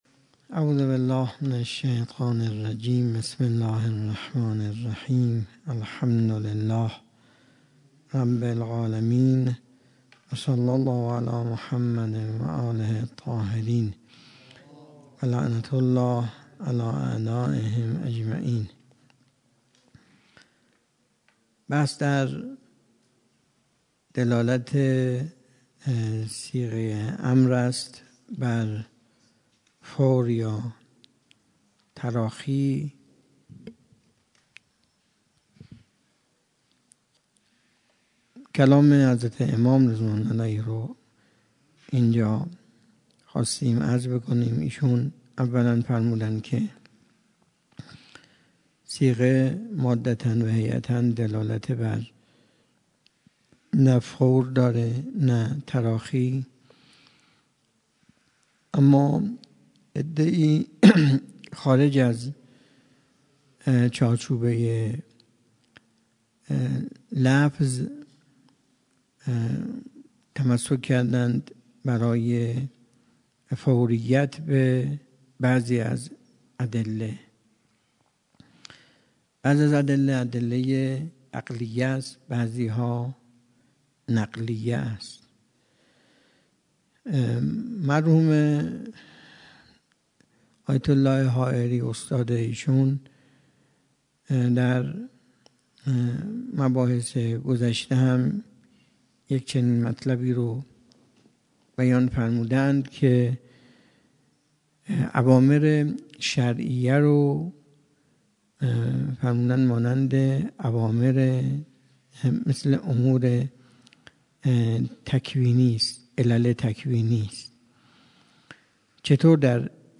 درس خارج
سخنرانی